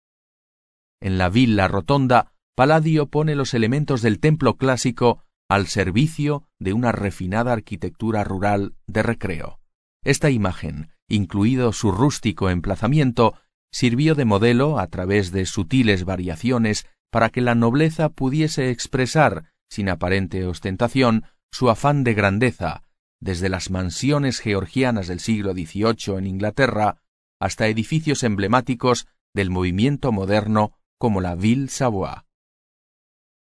Sprechprobe: Sonstiges (Muttersprache):
spanish voice over artist